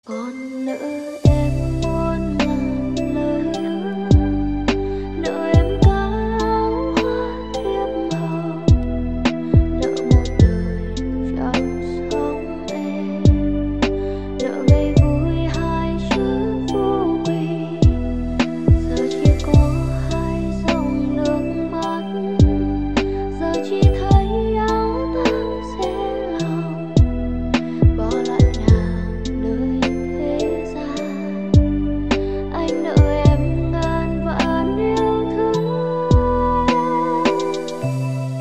Chạm đến trái tim bằng giai điệu dịu dàng